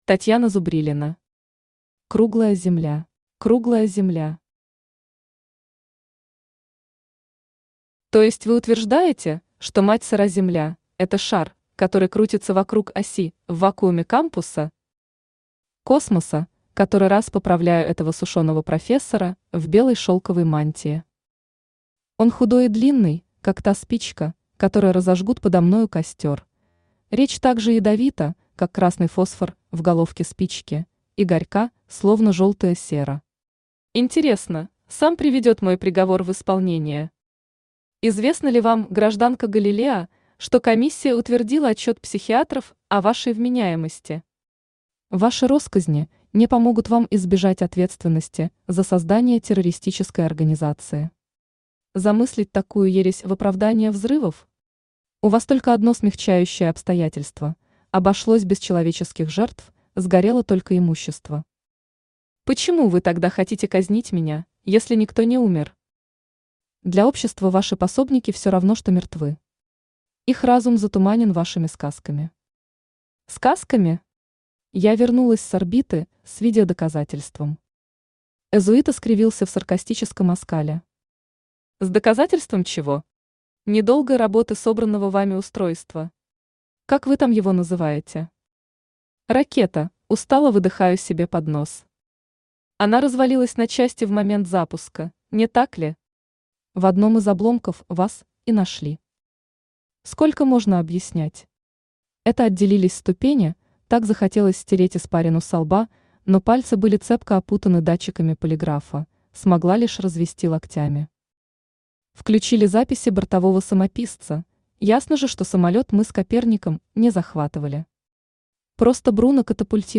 Аудиокнига Круглая Земля | Библиотека аудиокниг
Aудиокнига Круглая Земля Автор Татьяна Зубрилина Читает аудиокнигу Авточтец ЛитРес.